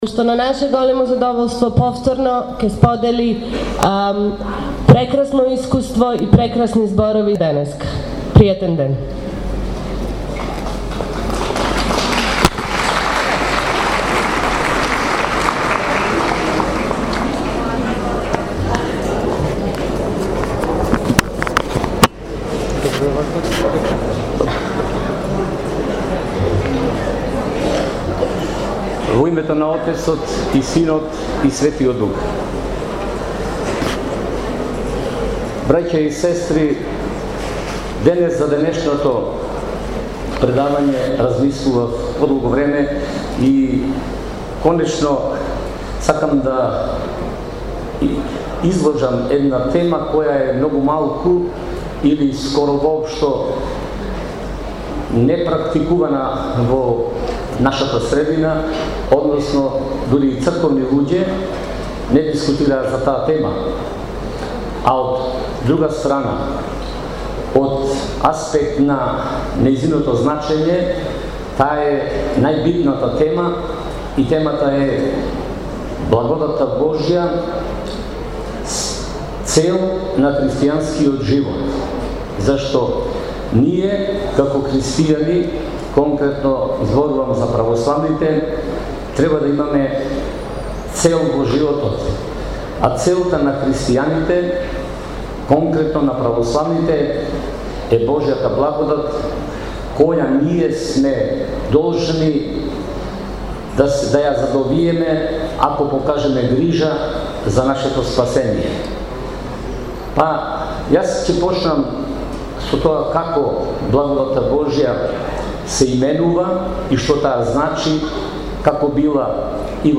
Во прилог, следува предавањето:
blagodatta-Bozhja-predavanje.mp3